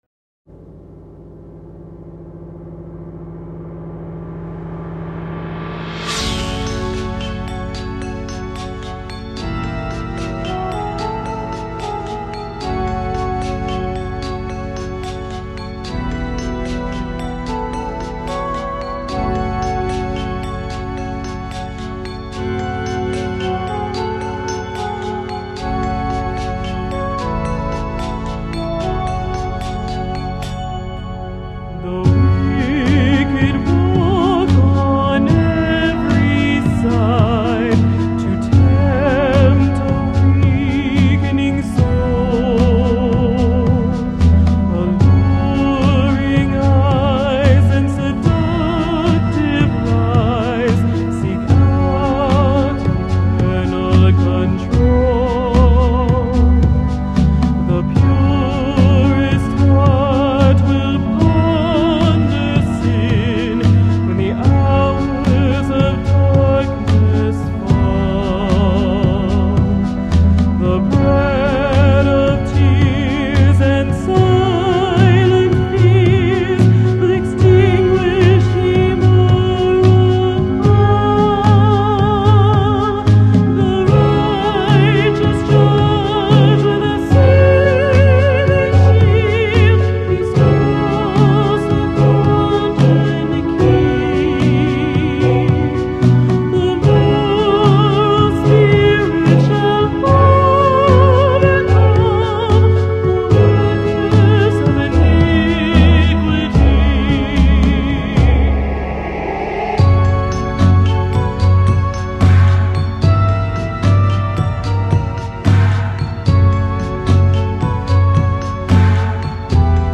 jazz
soundtrack-super! very appropriate!